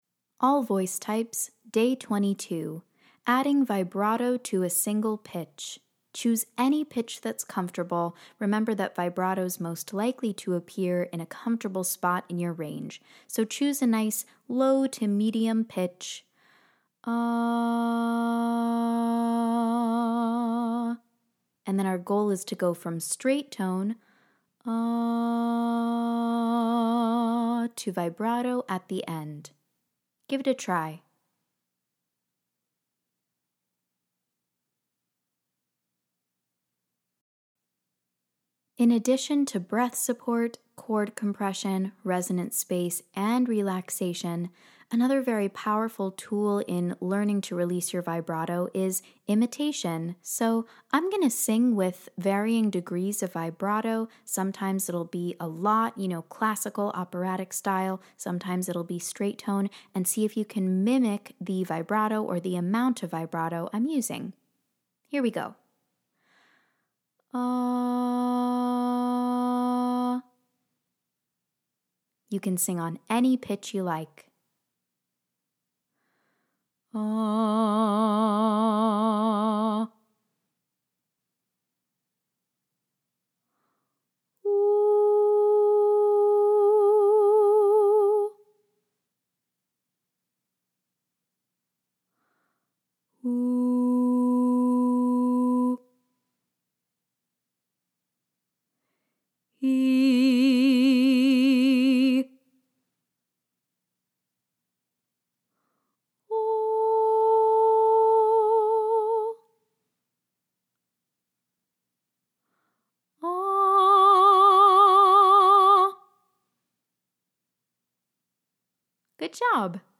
Day 22 - Bass - Vibrato Practice